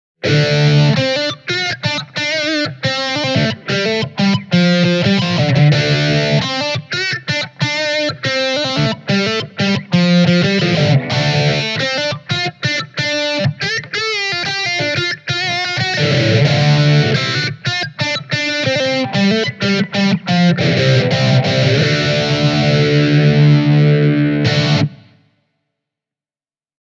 I played these clips on my Kasuga (a Japanese ES-335 copy from the Seventies), recording the sound with two dynamic Shure microphones.
Super Crunch: